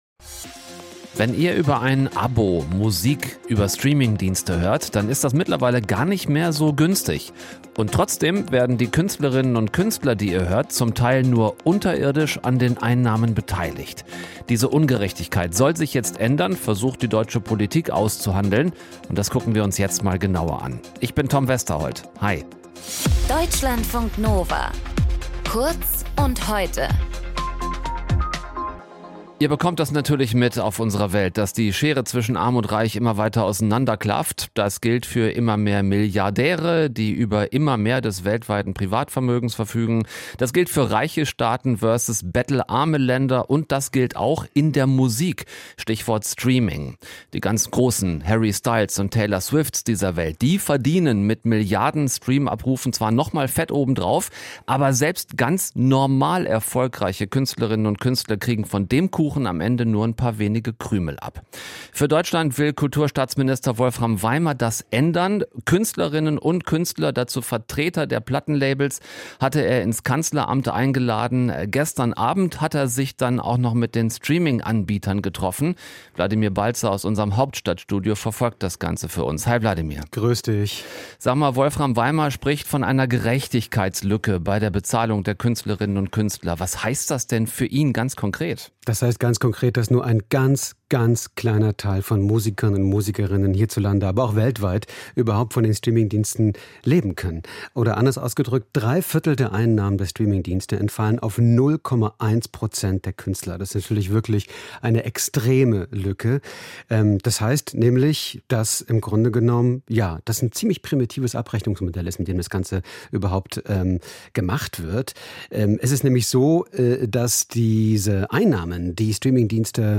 In dieser Folge mit: